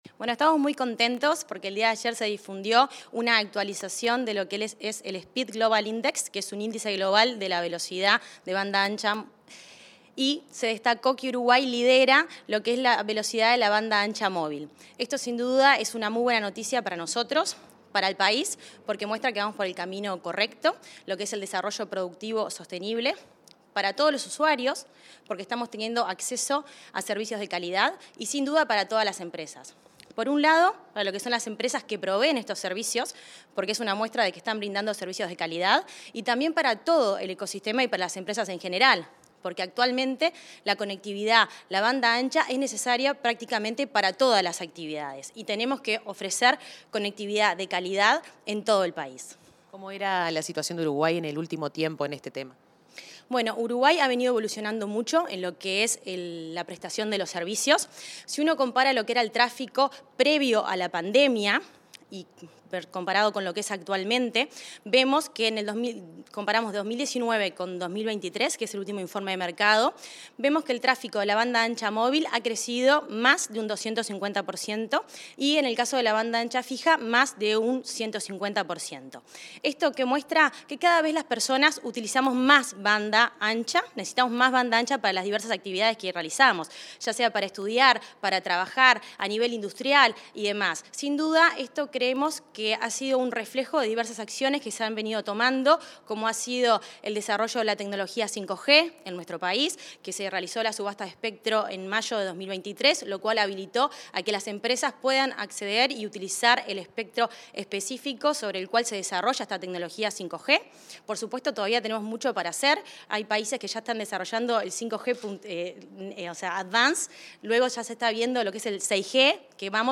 Entrevista a la presidenta de la Ursec, Mercedes Aramendía